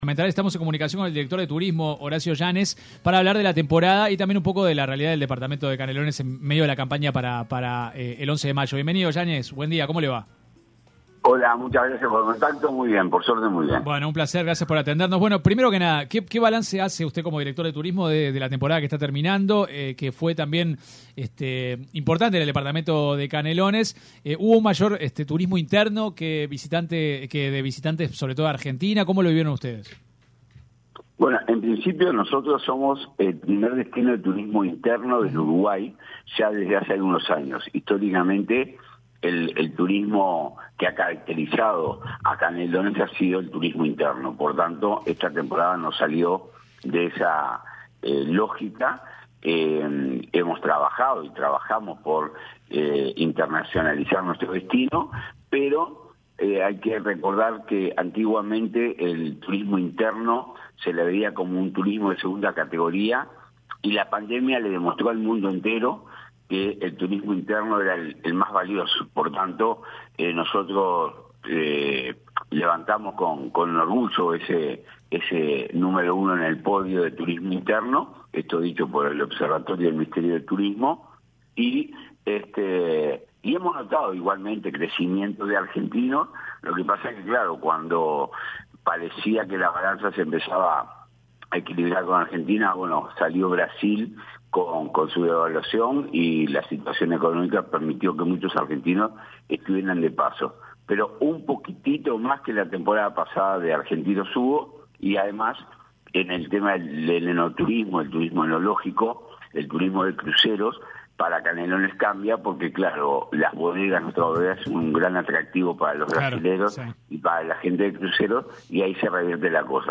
El actual director de Turismo de la Intendencia de Canelones, Horacio Yanes, explicó en una entrevista con 970 Noticias, cuál será su futuro luego de las elecciones departamentales de mayo próximo.